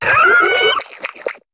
Cri de Tritosor dans Pokémon HOME.